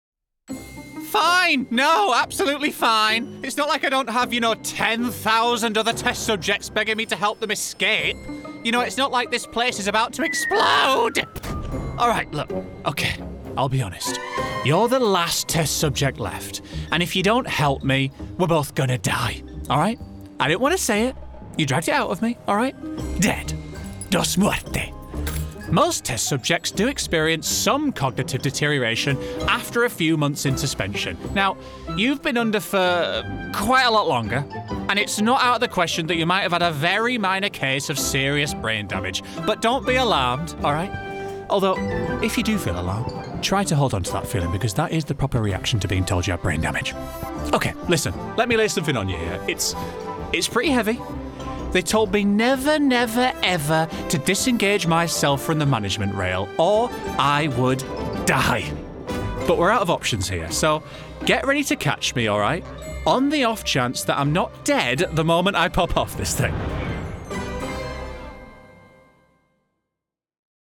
Character Showreel
Male
Yorkshire